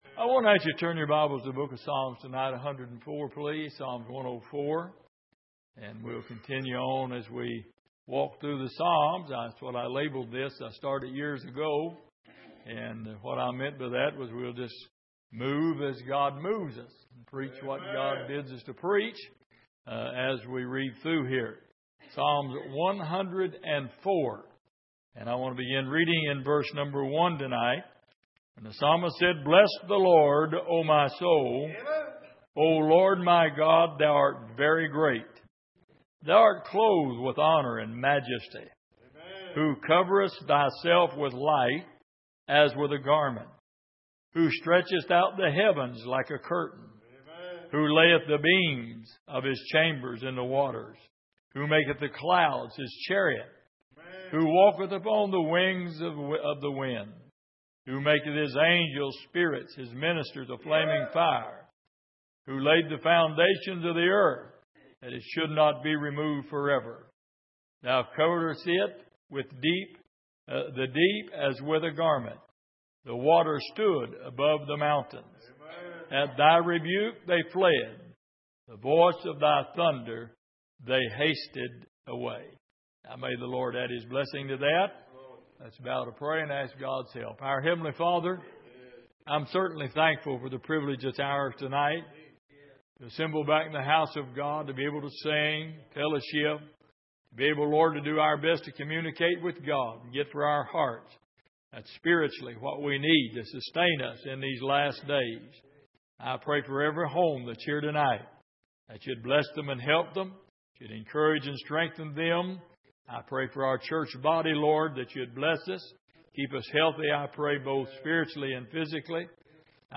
Passage: Psalm 104:1-7 Service: Midweek